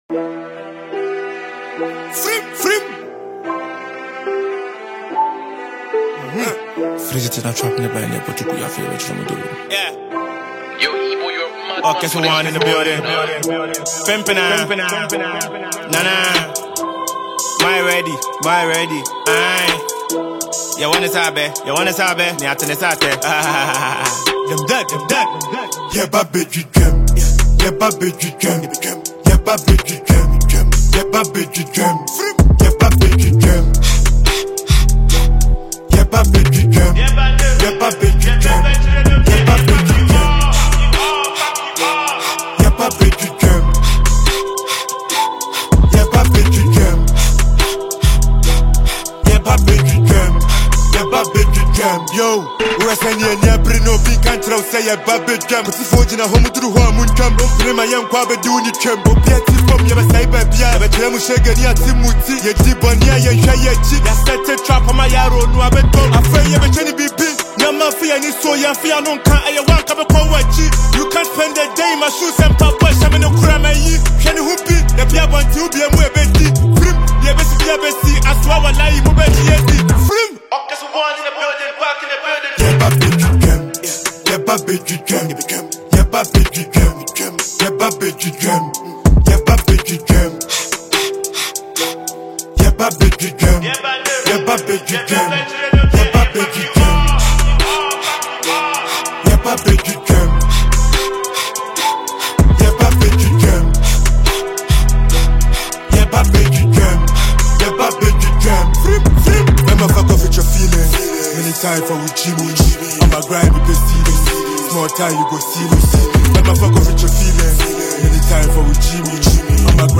high-energy hip-hop and drill anthem
• Genre: Hip-Hop / Drill